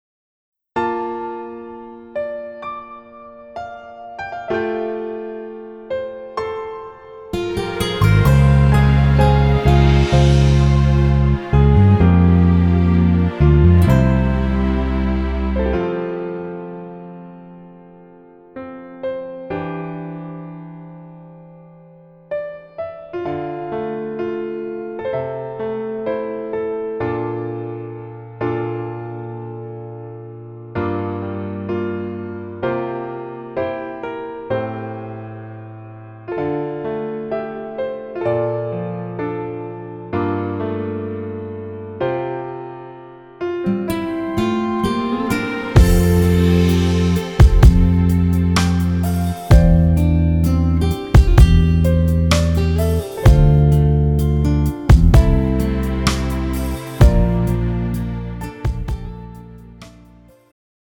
음정 여자-2키
장르 축가 구분 Pro MR